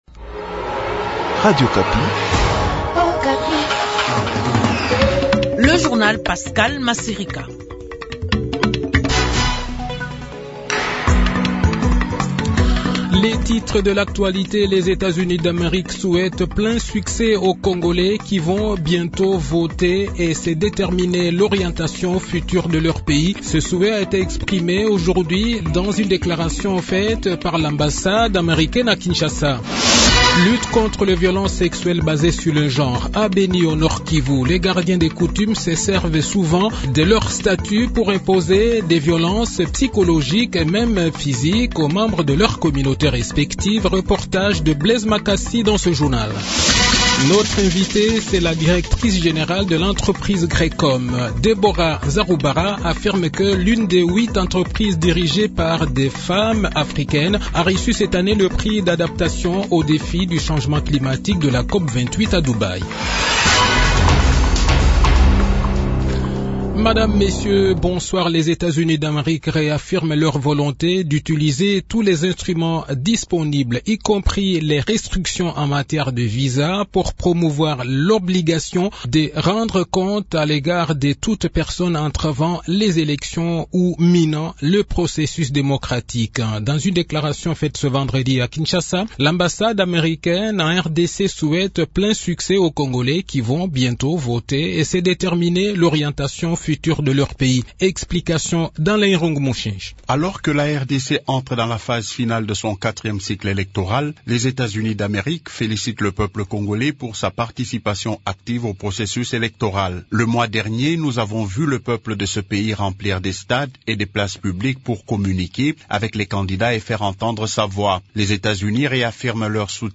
Le journal de 18 h, 15 Decembre 2023